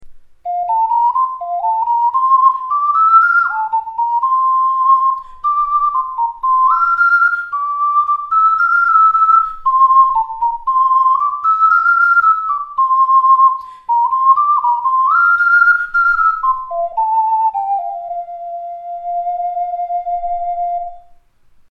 Окарина Focalink 12ACA-MC
Окарина Focalink 12ACA-MC Тональность: C
Керамическая окарина - альт.
Диапазон неполные две октавы. Классическая модель сочетающая в себе превосходный звук и легкость управления.